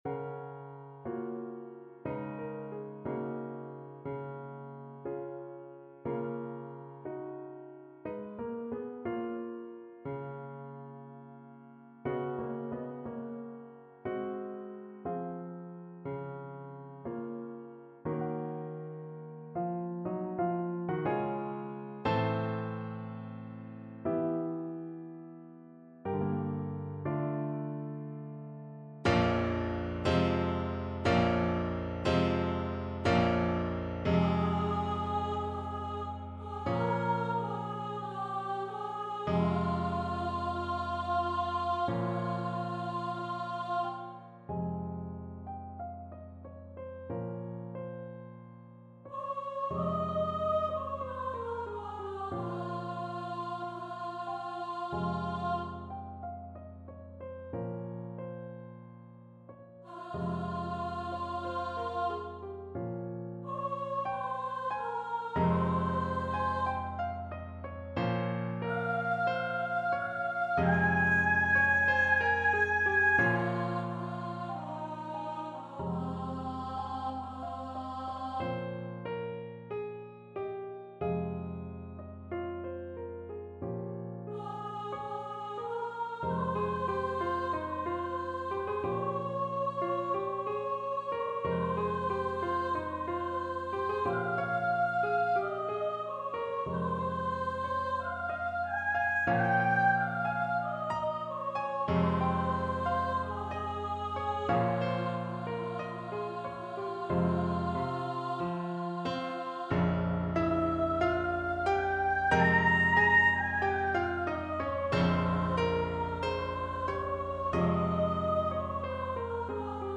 Lento
Classical (View more Classical Soprano Voice Music)